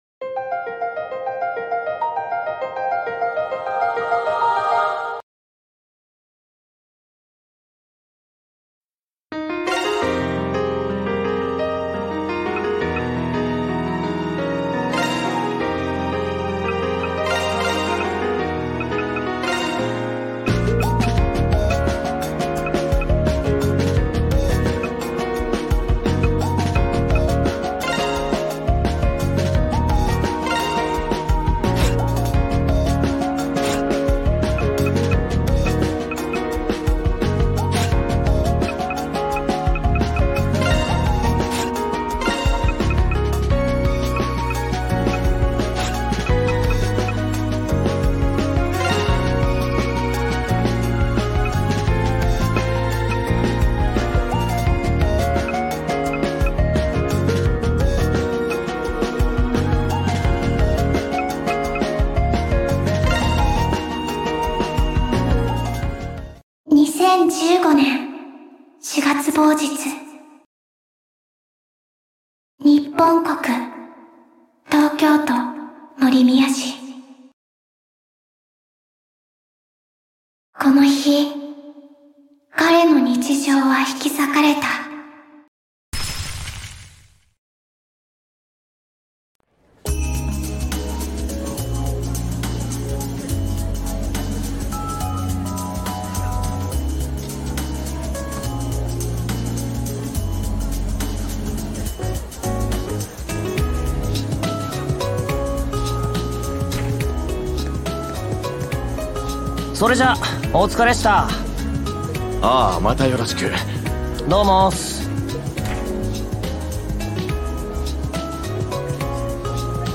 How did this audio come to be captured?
Game: Tokyo Xanadu Ex+ (Falcom) This footage was recorded years ago, so it might not be great.